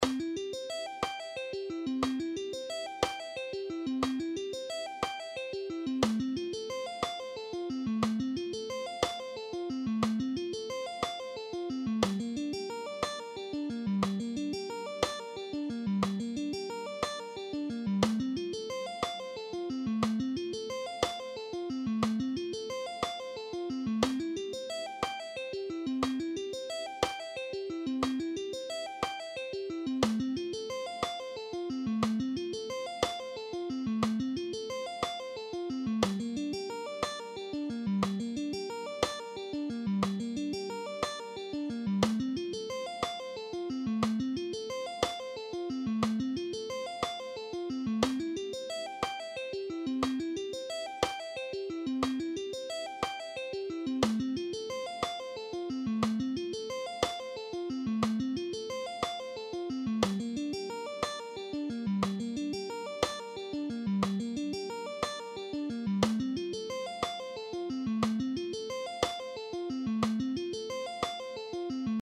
All these guitar exercises are in ‘C’
Sweep Picking Guitar Lesson
8.-Sweep-Picking-Guitar-Lesson.mp3